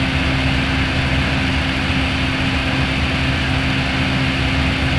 cfm-idle.wav